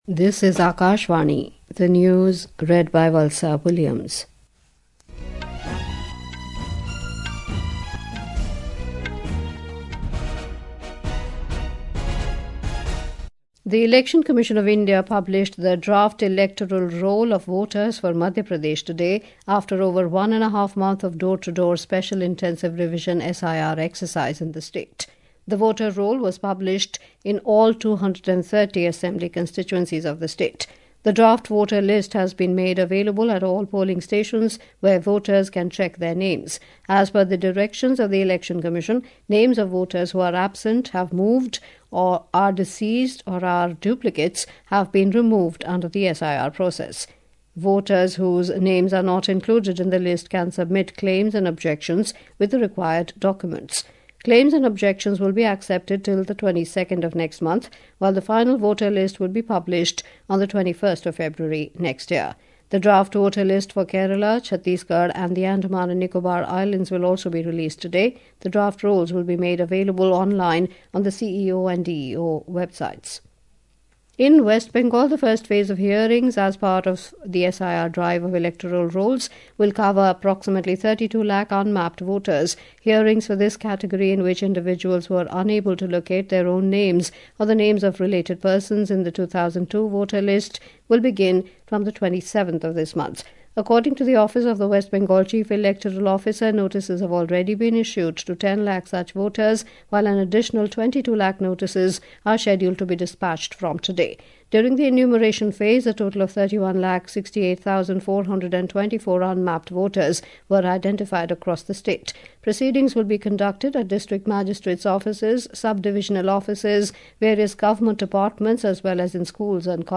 This audio news bulletin titled Hourly News in the category Hourly News .